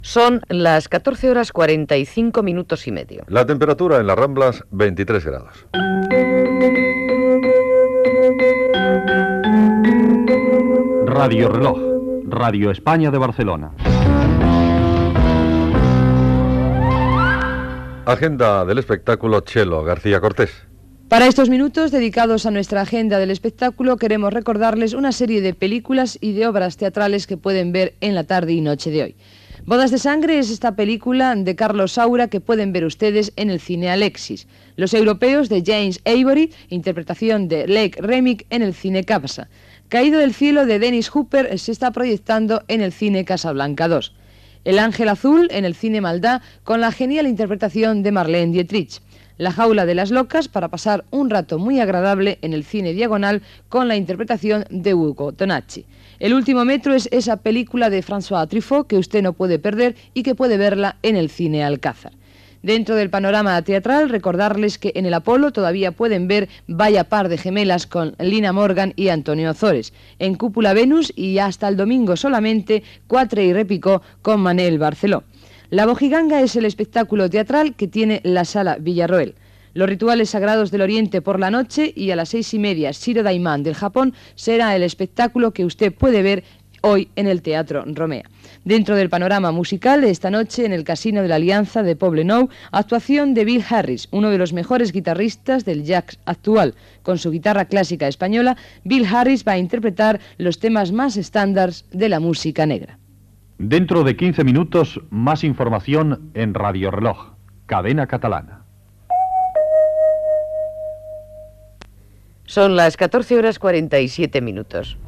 Toc de l'hora, indicatiu, agenda de l'espectacle, indicatiu i hora
Entreteniment